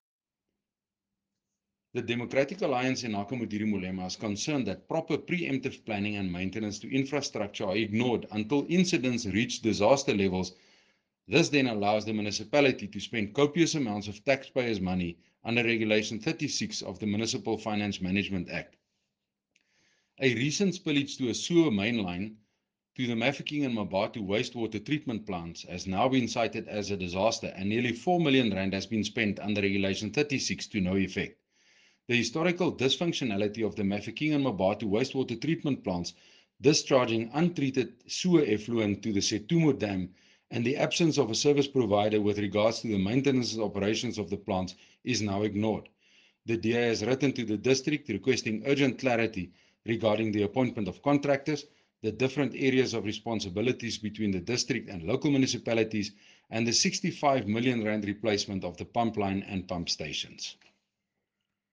Note to Broadcasters: Please find linked soundbites in
Cllr-Cornel-Dreyer-Sewage-Eng.mp3